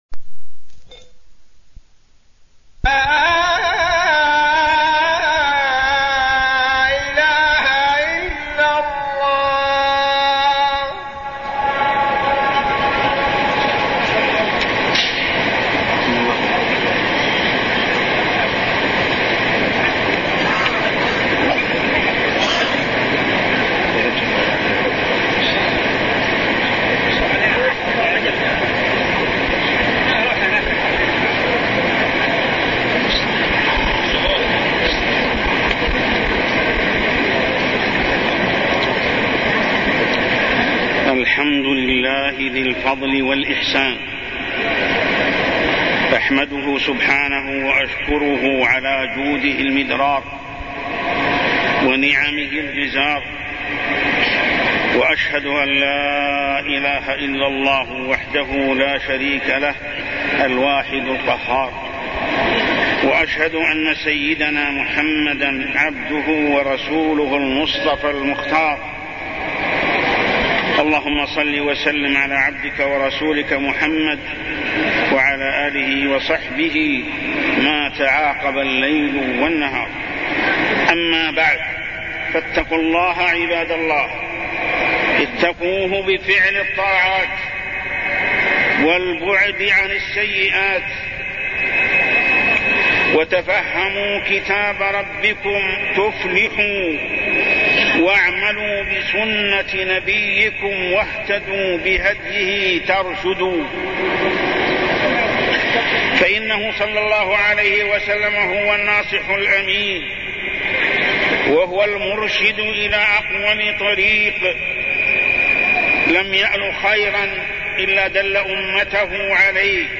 تاريخ النشر ٤ شعبان ١٤٢٠ هـ المكان: المسجد الحرام الشيخ: محمد بن عبد الله السبيل محمد بن عبد الله السبيل من وصايا النبي صلى الله عليه وسلم-اتق الله